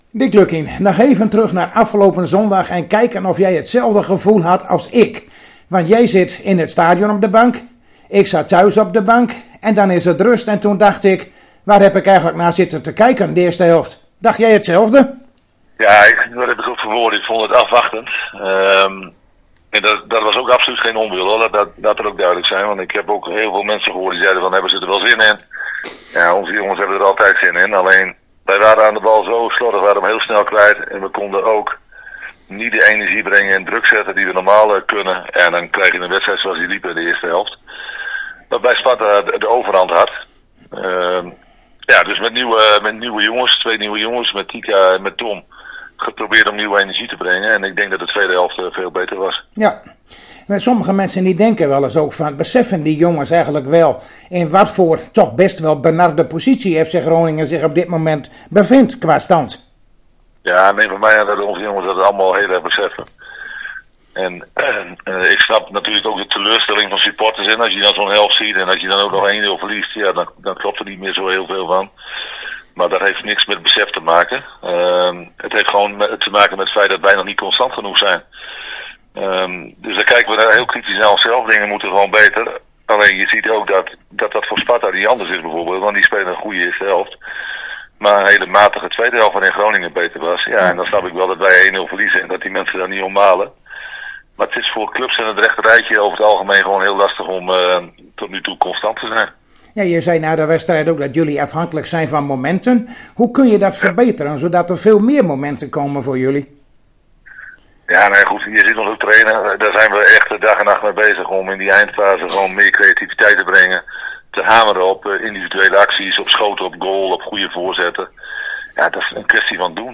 Zojuist spraken wij weer met Dick Lukkien over de wedstrijd van morgen FC Groningen - NEC Nijmegen.